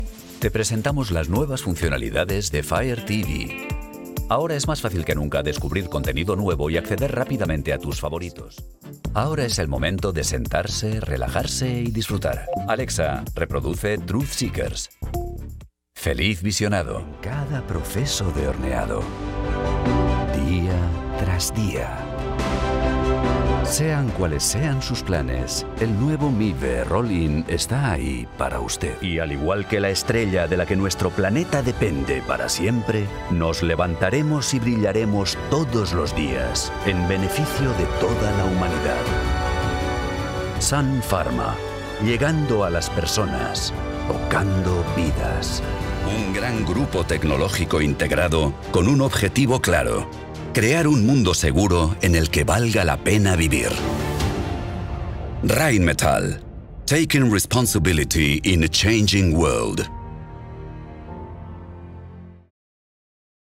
Deep, Distinctive, Versatile, Reliable, Warm
Corporate